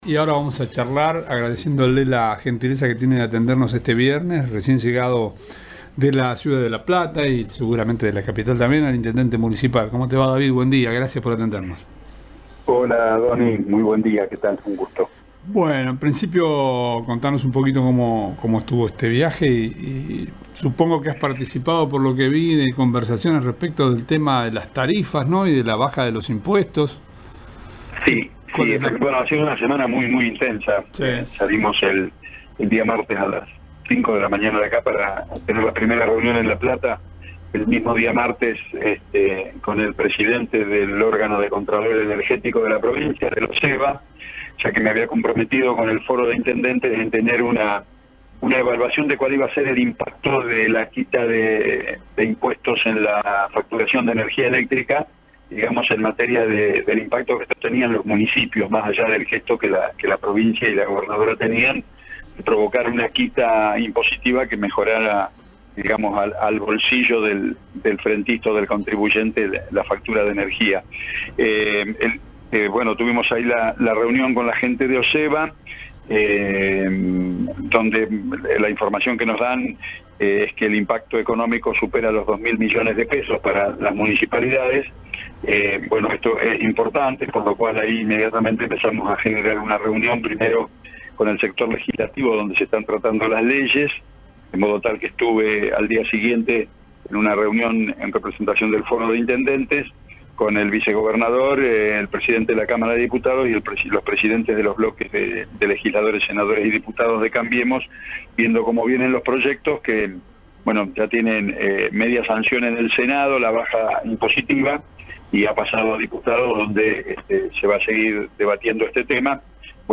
Entrevista exclusiva al Intendente Hirtz sobre temas de la gestión municipal